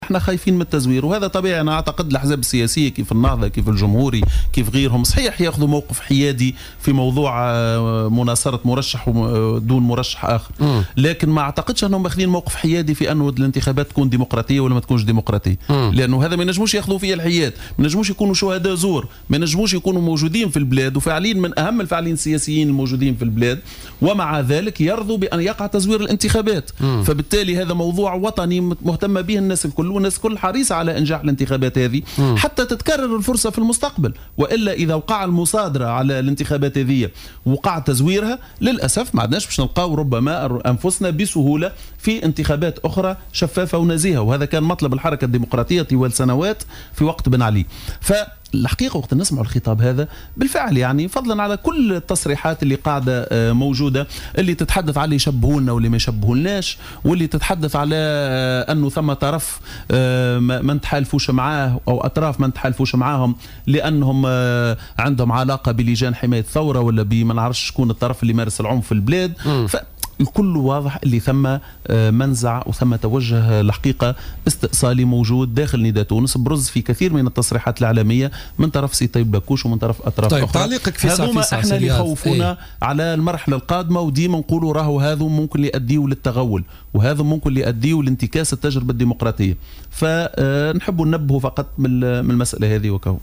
اعتبر الأمين العام لحزب البناء الوطني رياض الشعيبي في تصريح للجوهرة أف أم اليوم الاربعاء خلال حصة بوليتيكا، أن توجها استئصاليا برز داخل حزب نداء تونس يقوده الطيب البكوش من خلال تصريحاته المتتالية التي دعا فيها إلى إقصاء بعض الأطراف السياسية من المشهد.